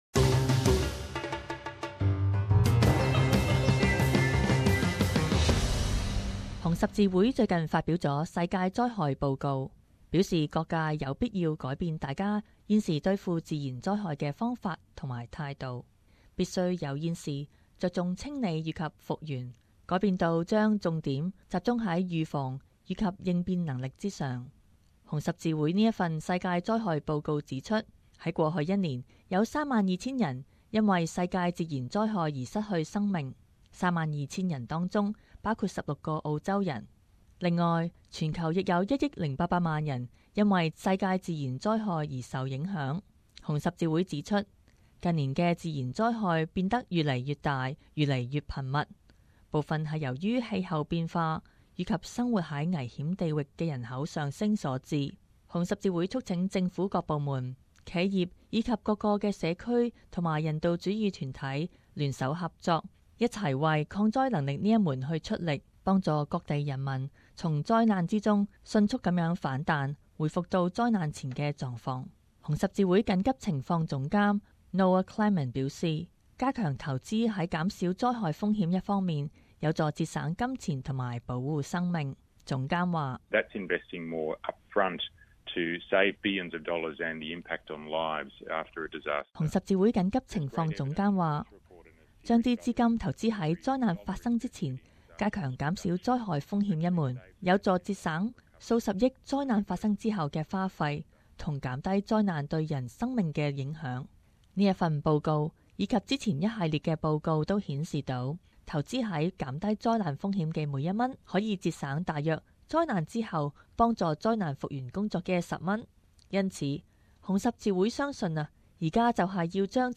【時事報導】世界災害報告